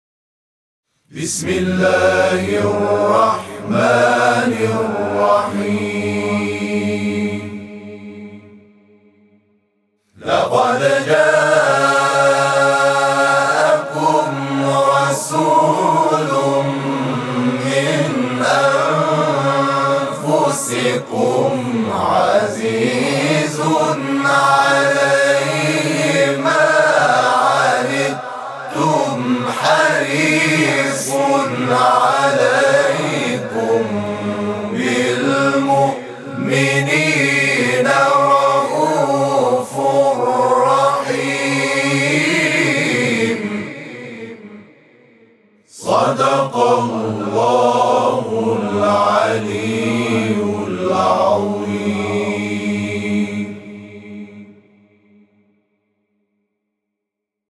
صوت همخوانی آیه 128 سوره توبه از سوی گروه تواشیح «محمد رسول‌الله(ص)»